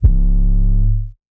SOUTHSIDE_808_drrt_C#.wav